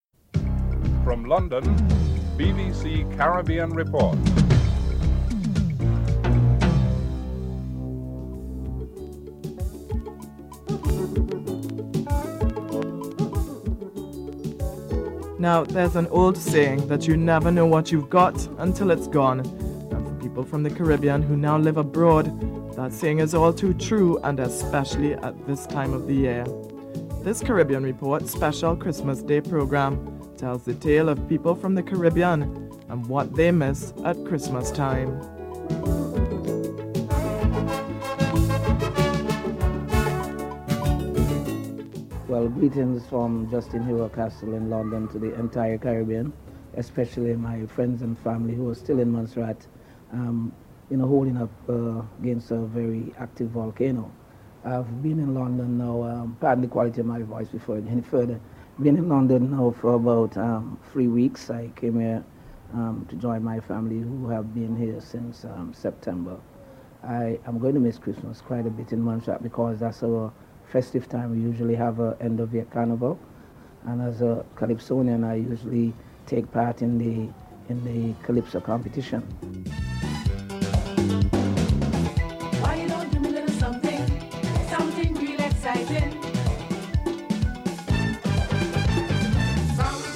1. Welcome to the special Christmas Day Edition: a look back at the year's events.
4. In Communist ruled Cuba Christmas is back for good.